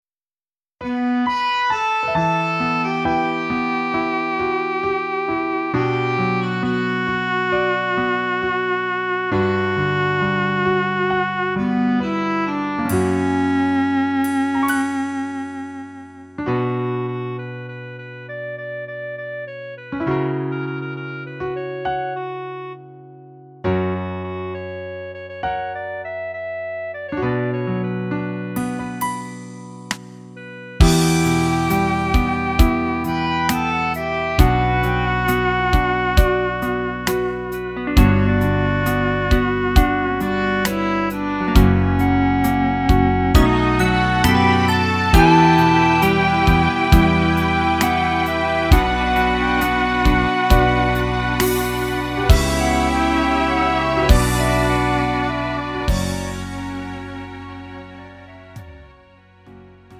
음정 -1키 3:47
장르 구분 Lite MR